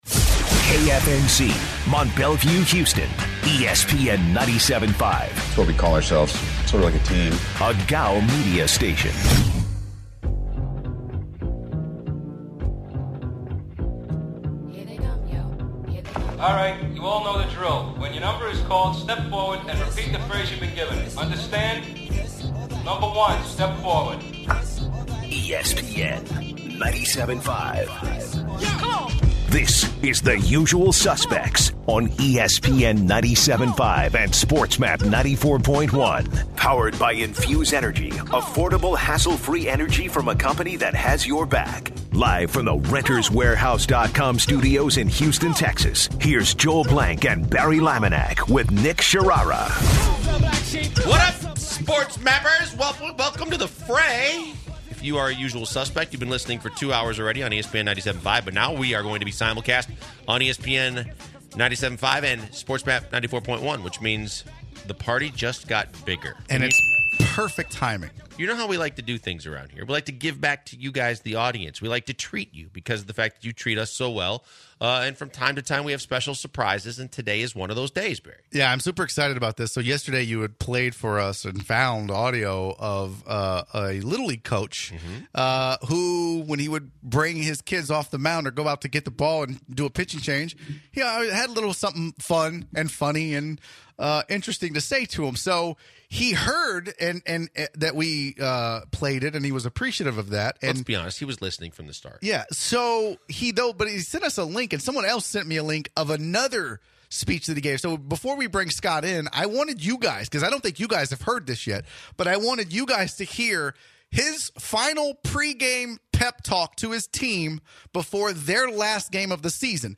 They also take calls from listeners.